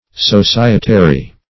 Societary \So*ci"e*ta*ry\